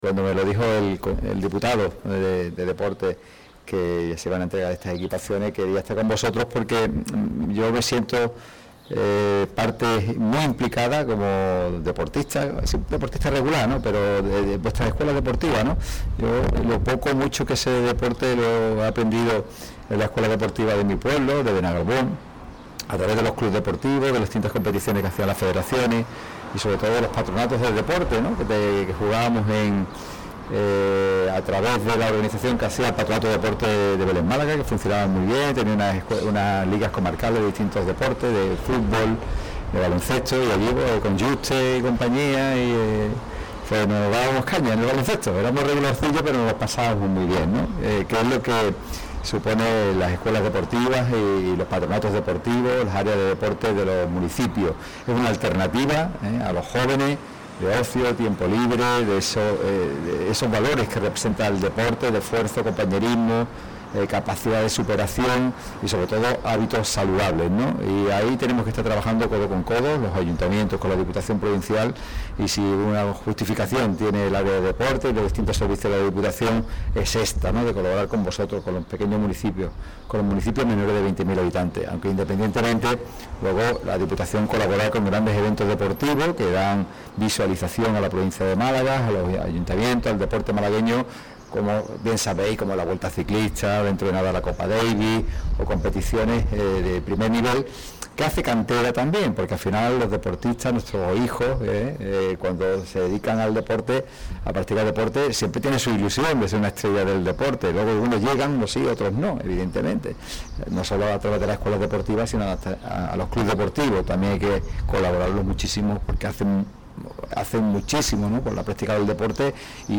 Más de 80 escuelas deportivas de la provincia han renovado sus equipaciones gracias a la Diputación de Málaga, que hoy ha presentado el material, que luce la nueva imagen corporativa de la institución, en un acto celebrado en la sede de la Diputación al que han acudido alcaldes, representantes de los ayuntamientos participantes en el programa y técnicos y responsables de las propias escuelas.